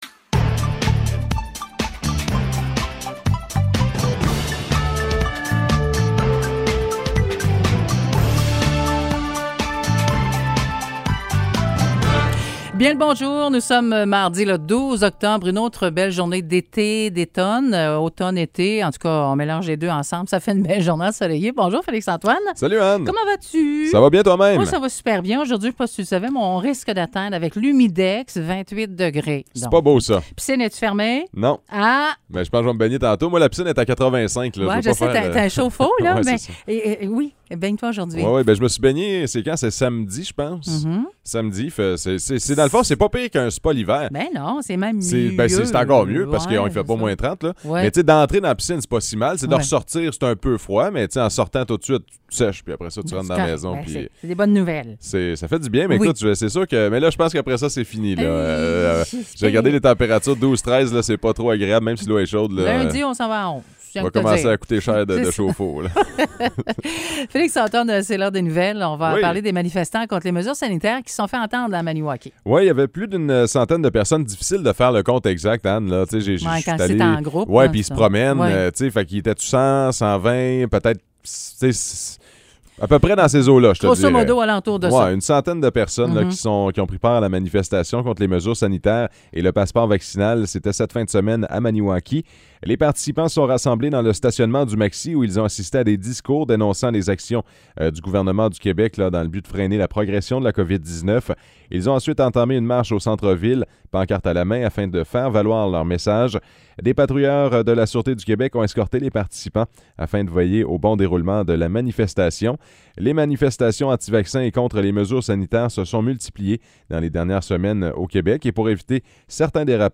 Nouvelles locales - 12 octobre 2021 - 9 h